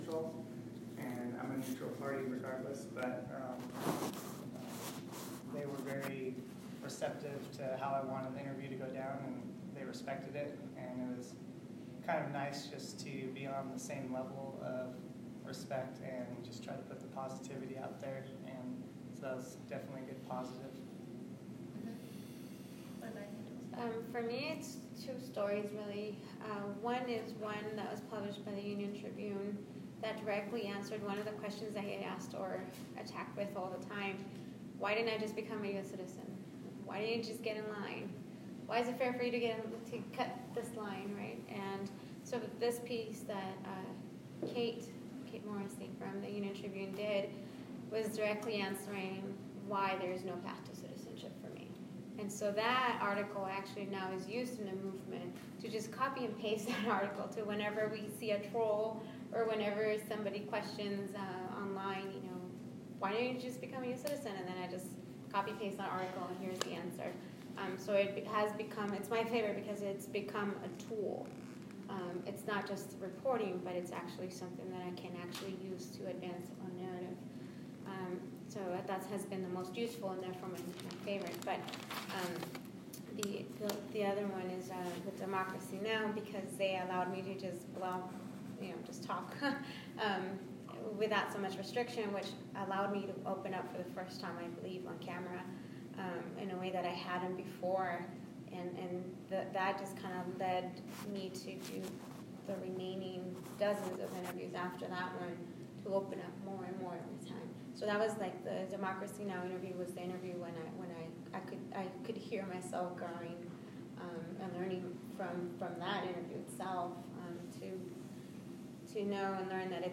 Click for audio of “Grade the Media” forum at Point Loma Nazarene University.